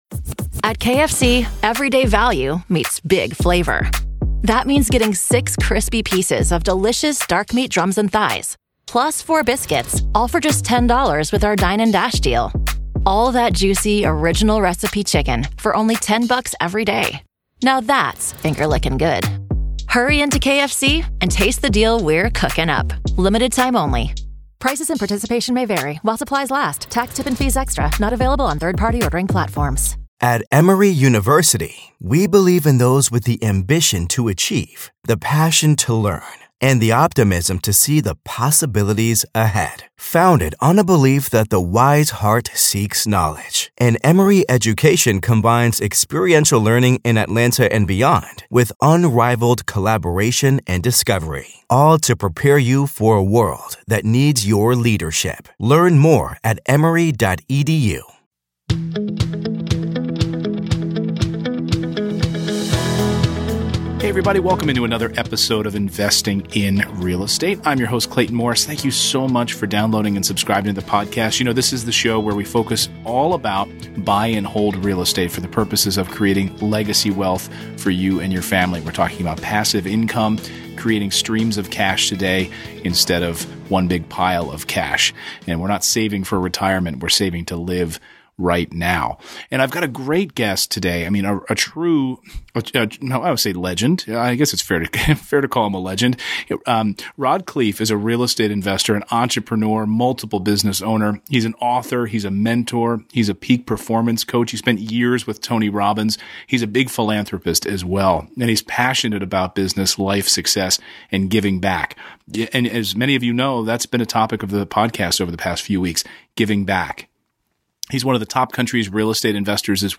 EP113: Using Visualization to Build Wealth and a Brilliant Life - Interview